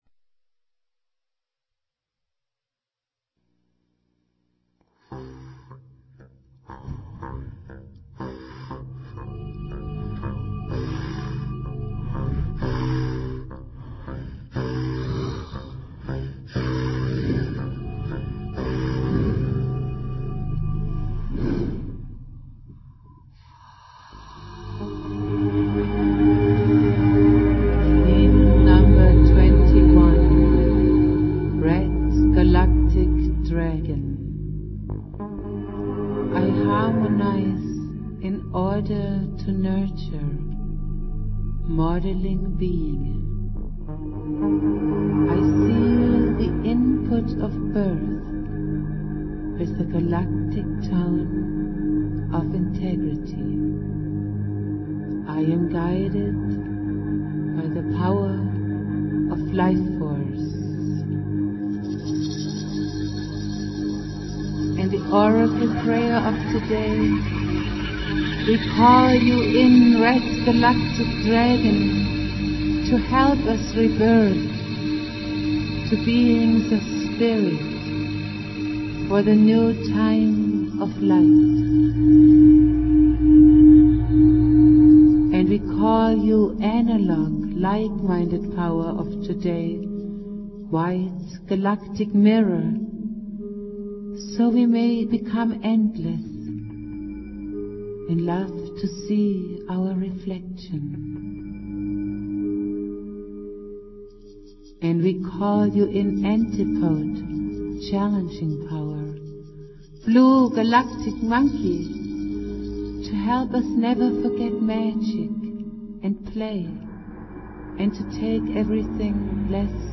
Prayer
flute